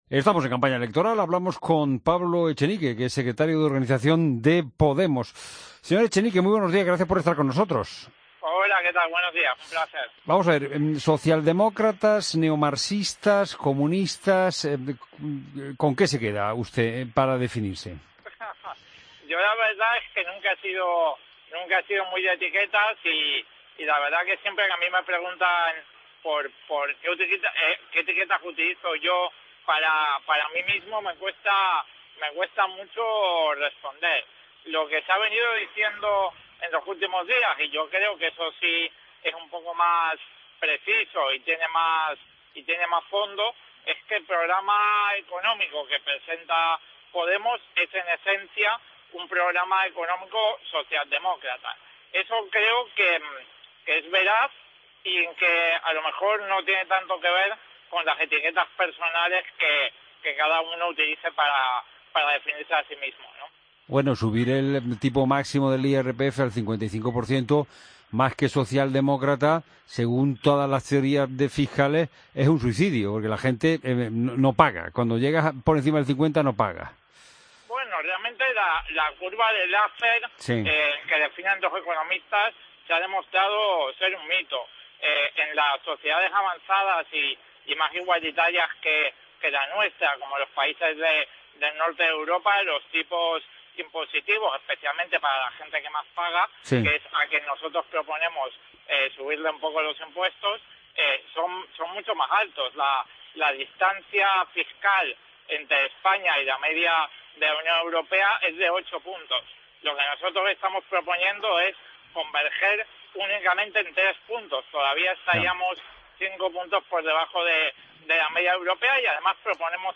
Escucha la entrevista a Pablo Echenique en La Mañana de Fin de Semana